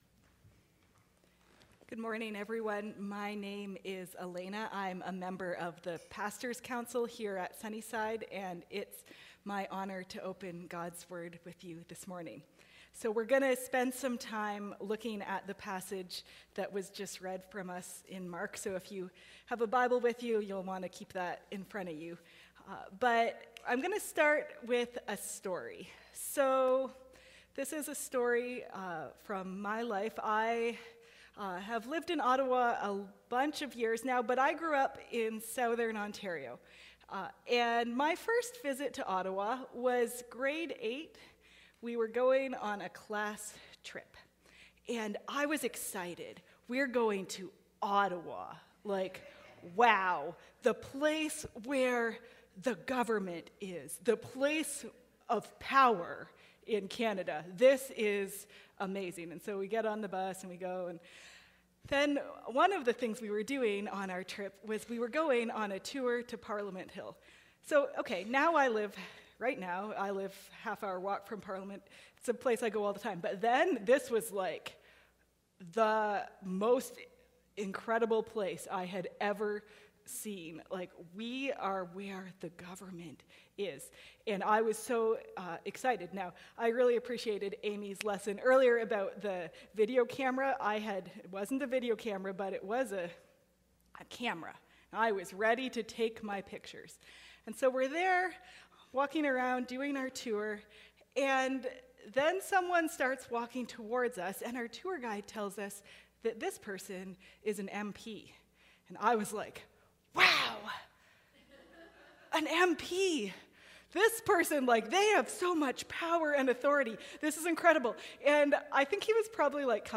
Family Service